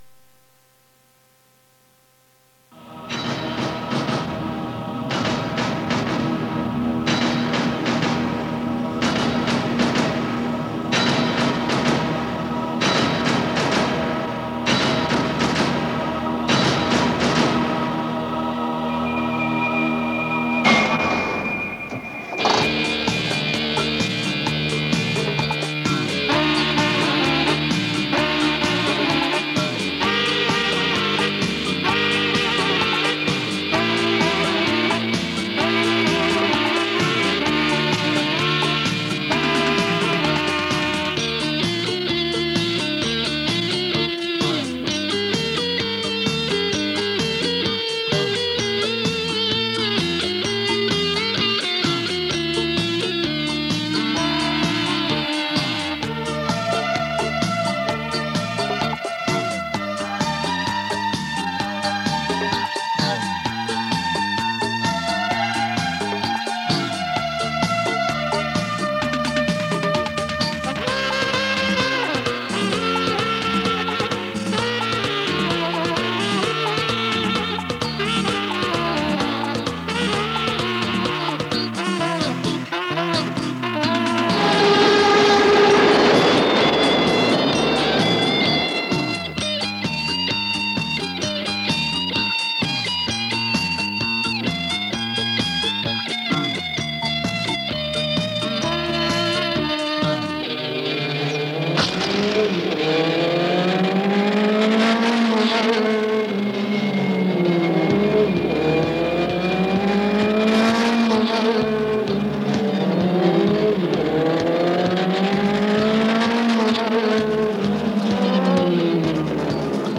高科技的超感度Hi-Fi 立体声高传真专业专用带